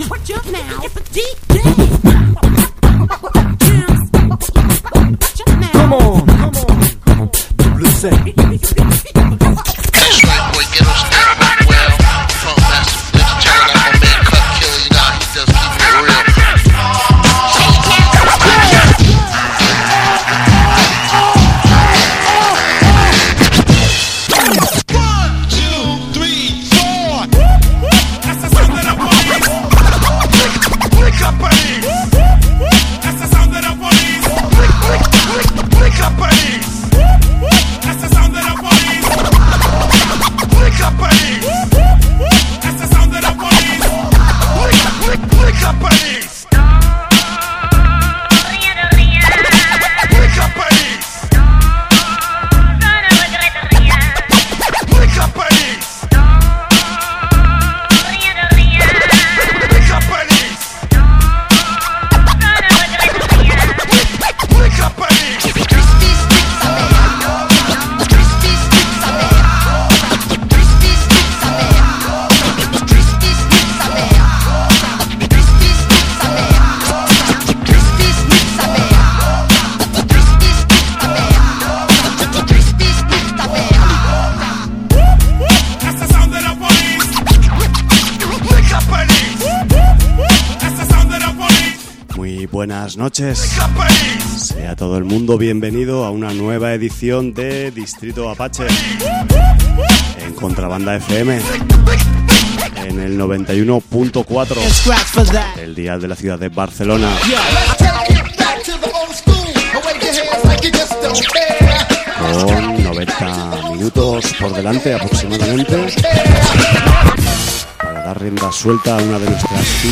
rap estatal